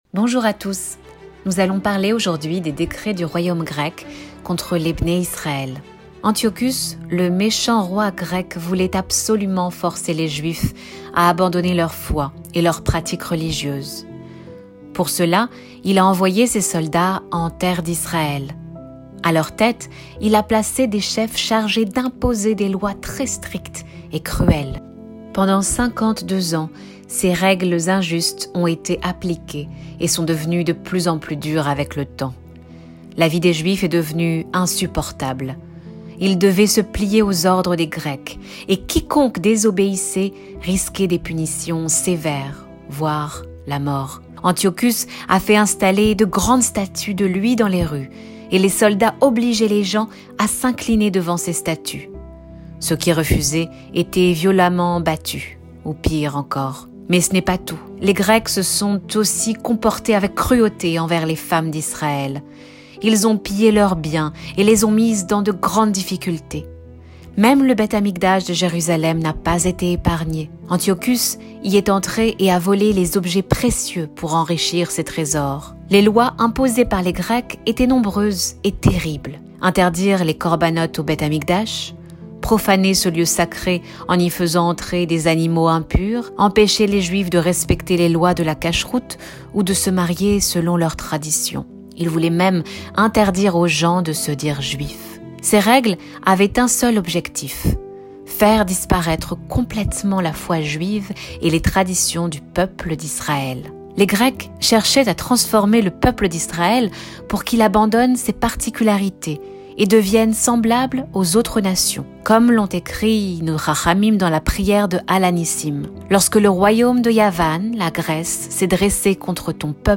L'équipe de Torah-Box est très heureuse de partager avec vous l'histoire de 'Hanouka, racontée en 8 épisodes. Ce format audio a été conçu spécialement pour agrémenter les 8 jours de la fête, afin que vos enfants puissent écouter le déroulé de la grande histoire de 'Hanouka et s'imprégner chaque jour des miracles et prodiges qu'Hachem a faits alors pour le peuple juif.